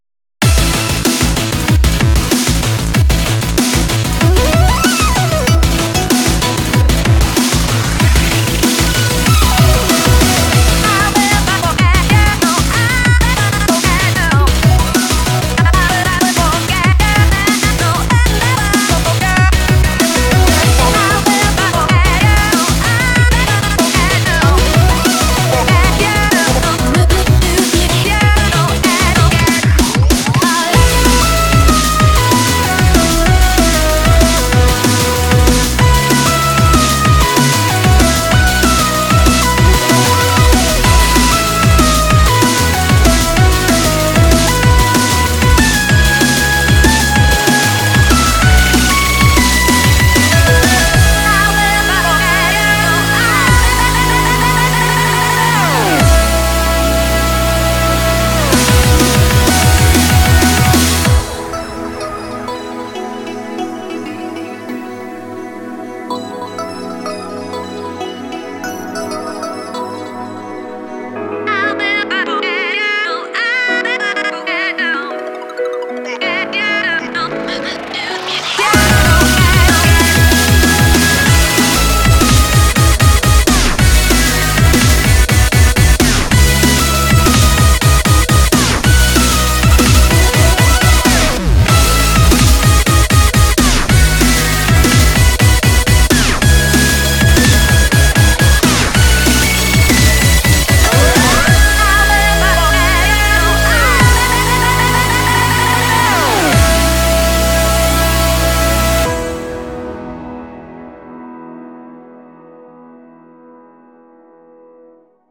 BPM48-190
Audio QualityPerfect (High Quality)
Comments[LEMONADE FUNK]